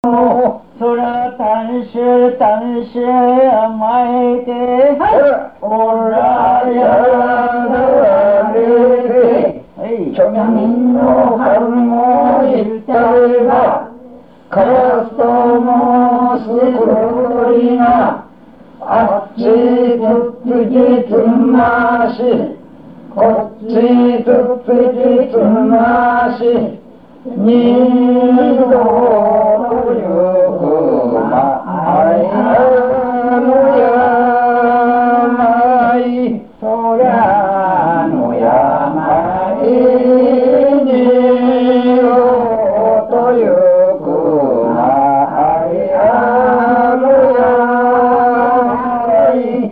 故郷の盆唄 たんし たんし
昔の様子を、いつしか盆踊り唄として歌われたようである。